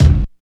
28.03 KICK.wav